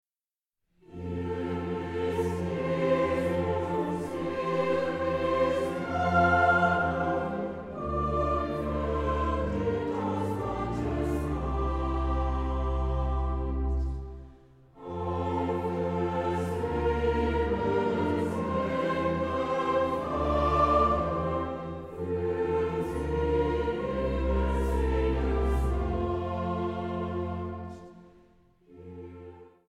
Feierstunde in Berlin-Lichtenberg am 6. November 2010